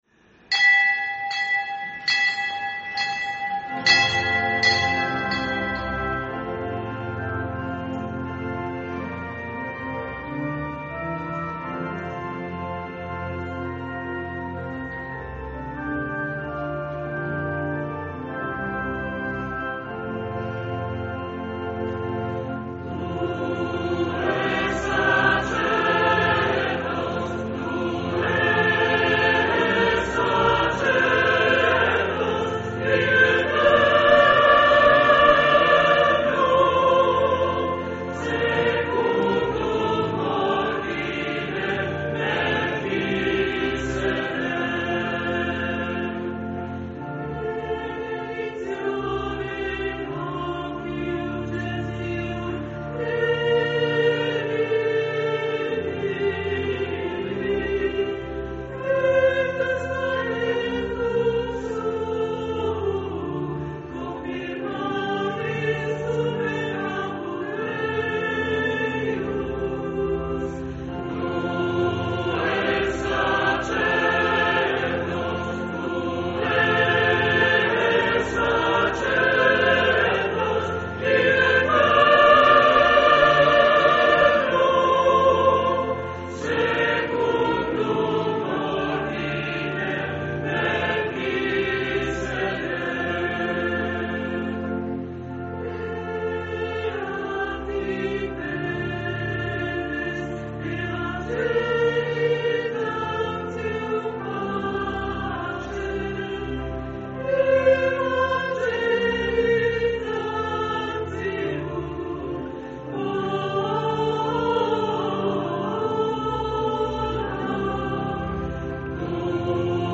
accompagnata dal Coro Interparrocchiale
CANTO DI APERTURA